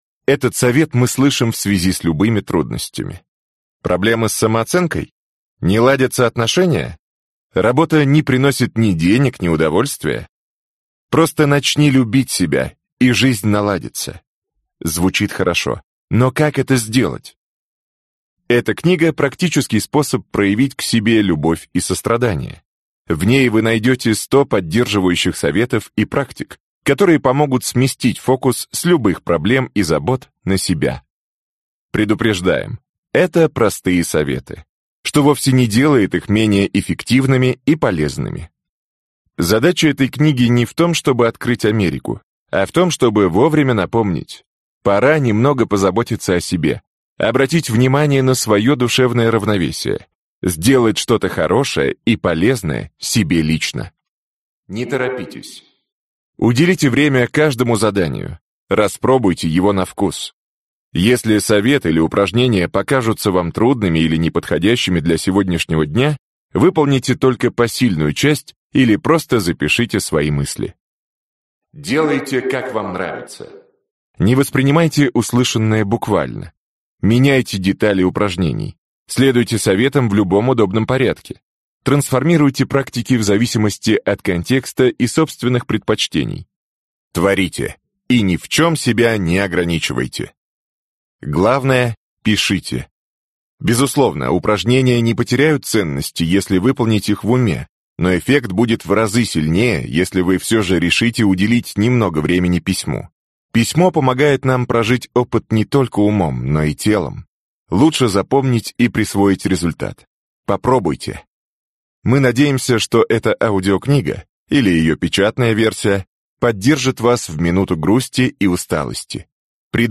Аудиокнига +100 любви к себе